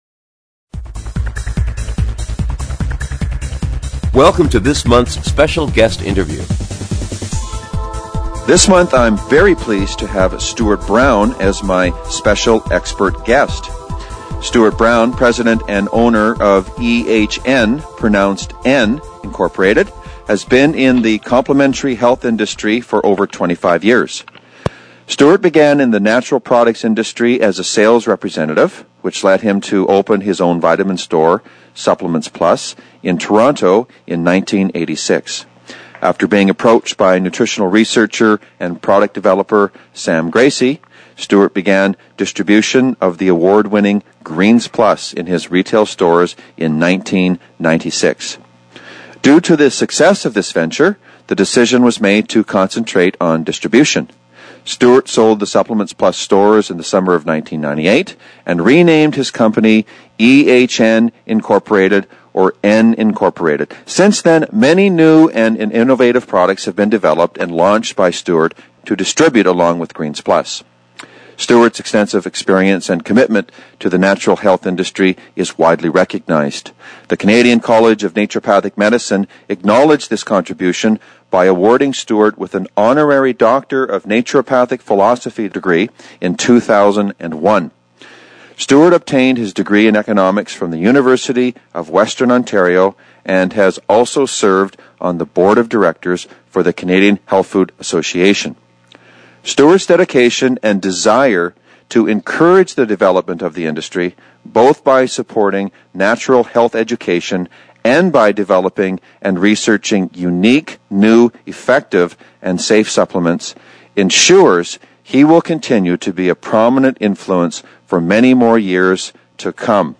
Special Guest Interview Volume 3 Number 8 V3N8c